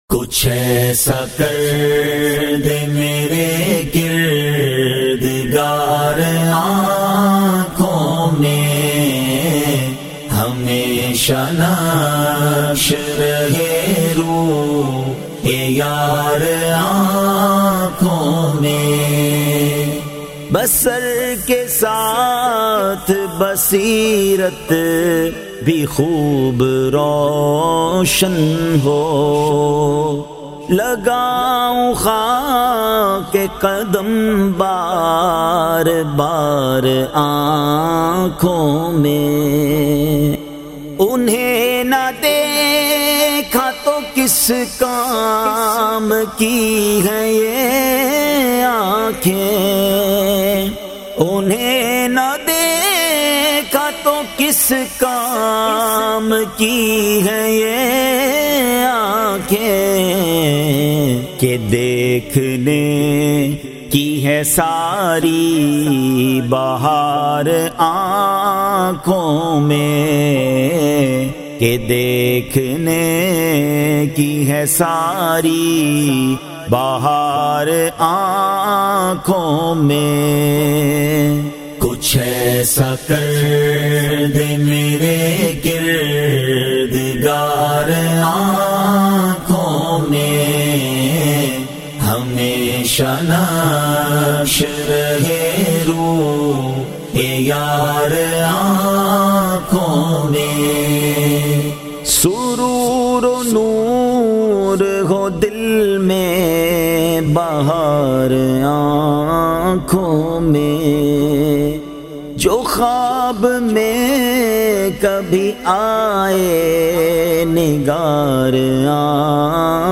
New Kalam 2025